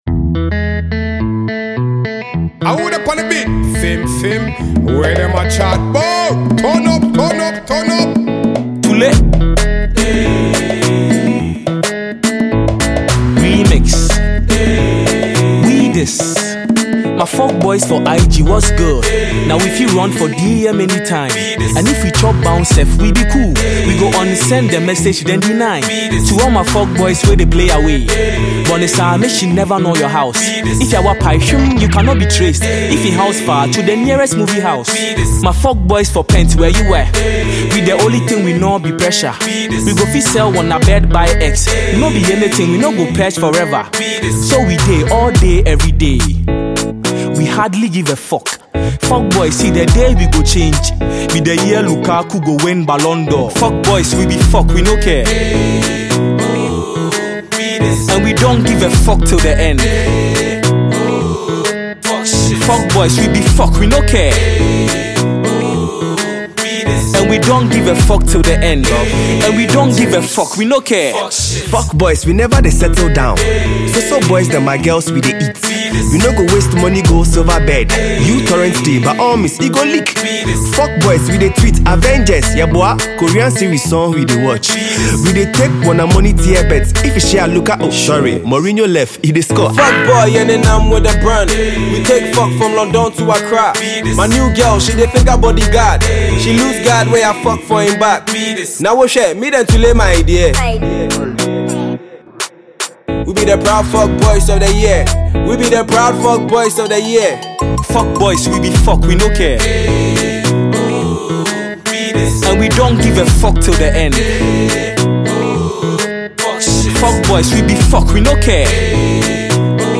Ghanaian talented rapper
official Ghana remix